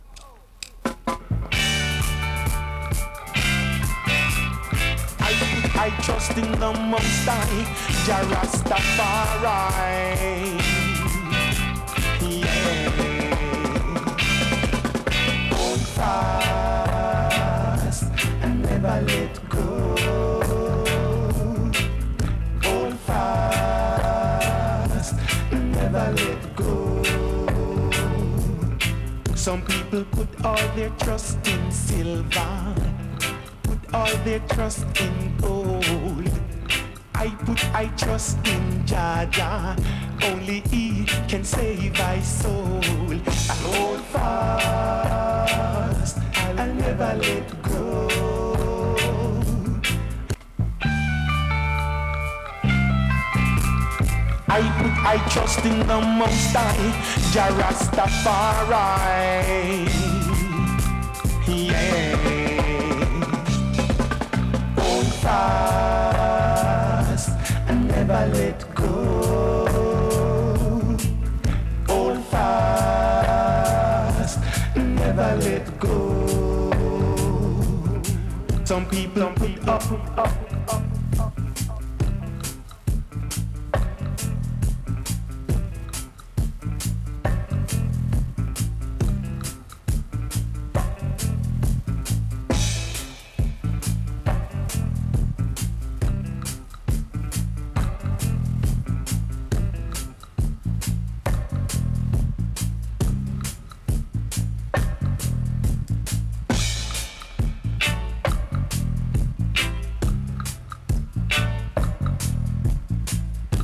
1970’s dubplate style